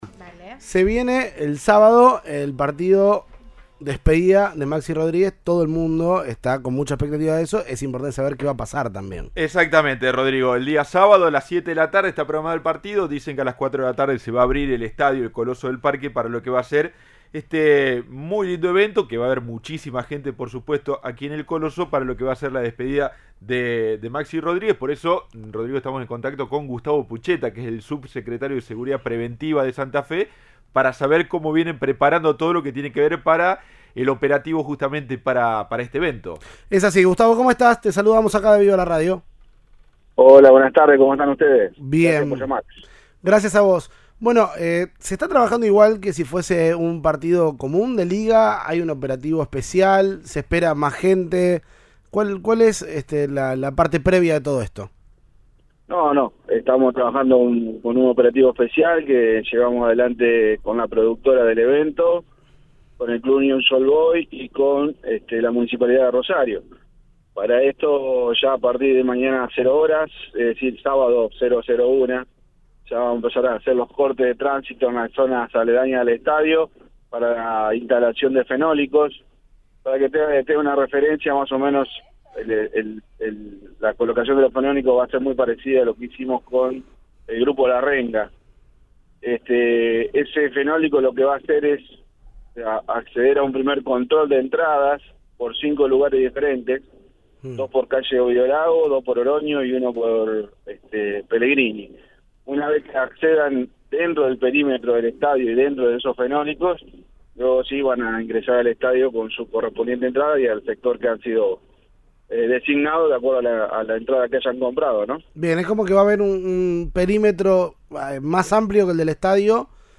El subsecretario de Seguridad Preventiva de la provincia de Santa Fe, Gustavo Puchetta, detalló en Cadena 3 Rosario cómo se disponen medidas de seguridad y cortes de tránsito en la zona del Coloso.
El subsecretario de Seguridad de Santa Fe precisó como planean custodiar a Messi.